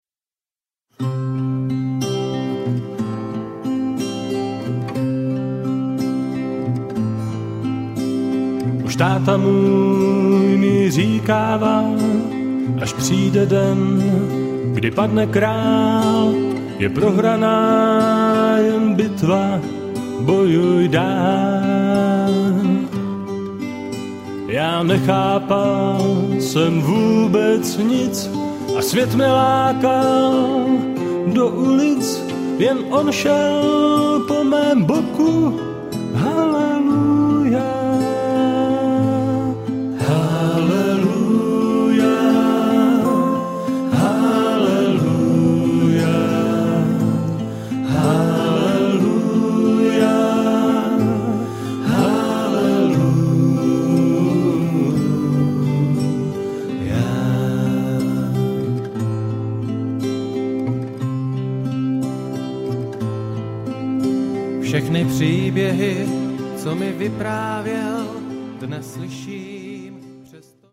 Žánr: Pop.